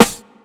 CDK - HB Snare.wav